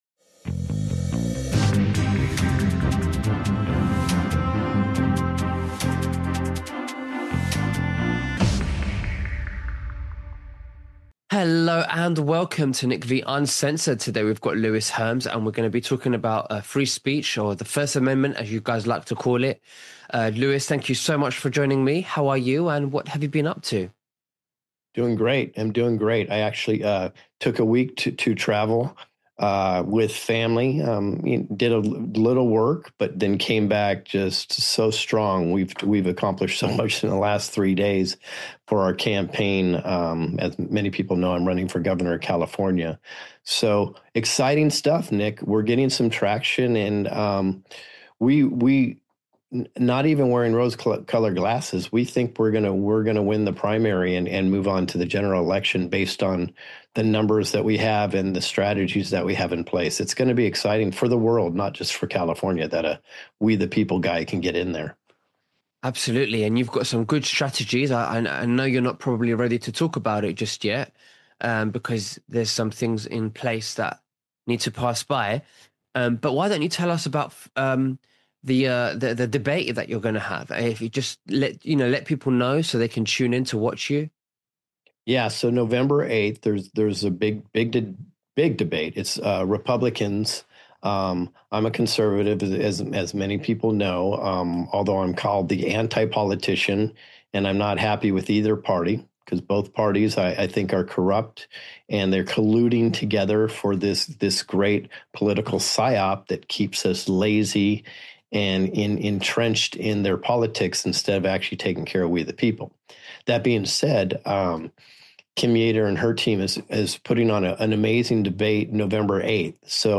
In this interview